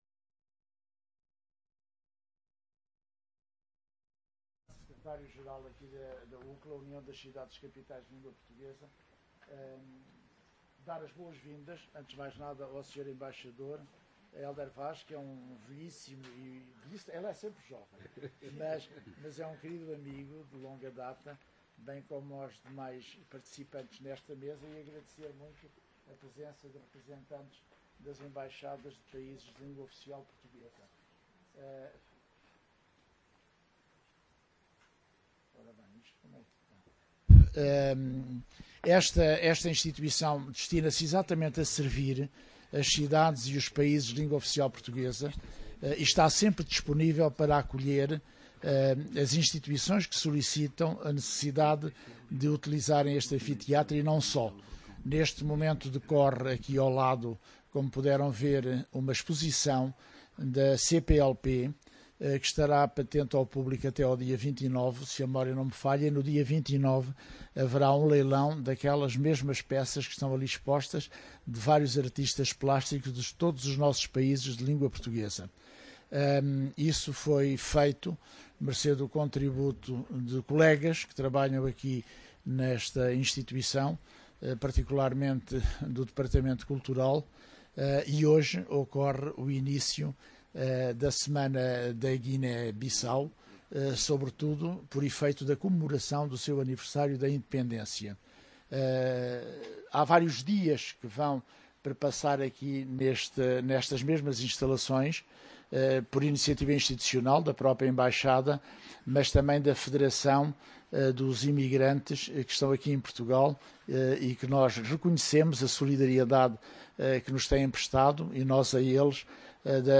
Decorreu no dia 18 de setembro, no auditório da UCCLA, a palestra subordinada ao tema “Investimento nos Bijagós”, no âmbito da Comemoração dos 44 Anos da Independência da Guiné-Bissau.